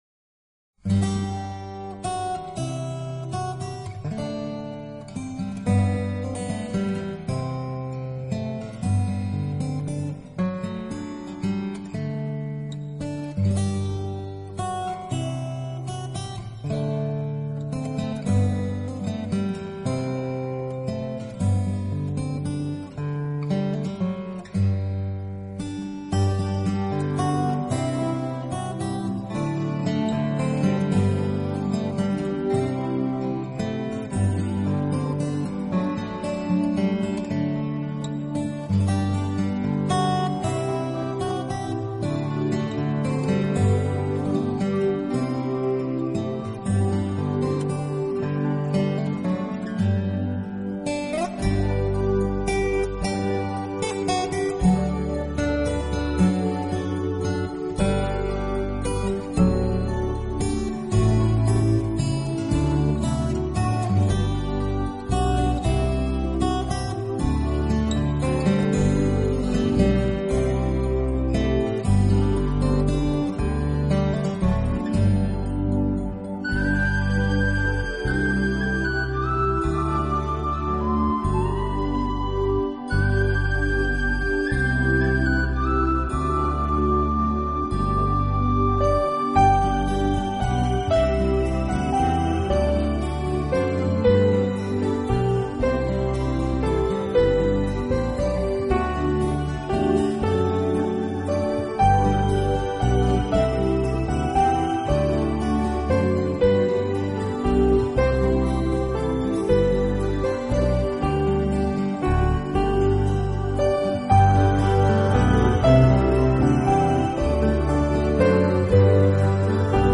音乐类型：New Age
原上，轻松，无忧，怀古，就象品尝古老浓醇的威士忌的爱尔兰咖啡。